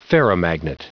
Prononciation du mot ferromagnet en anglais (fichier audio)
ferromagnet.wav